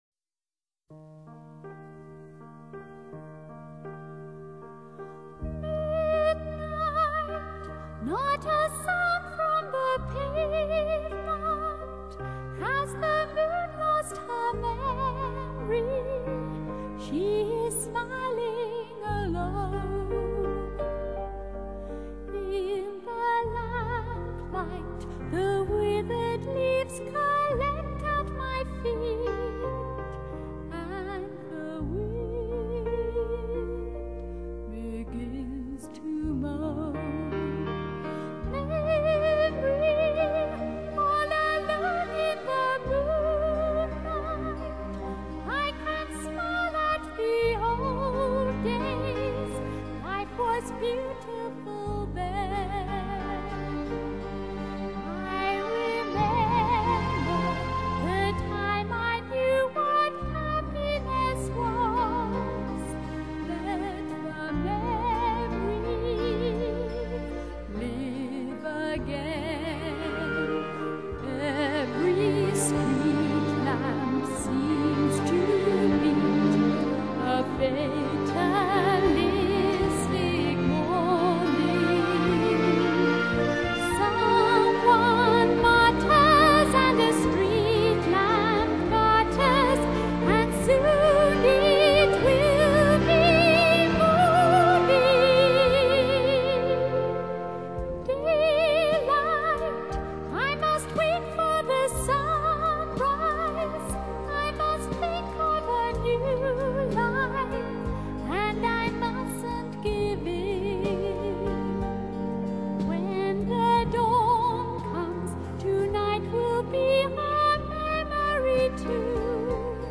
Vocal, Pop, Classical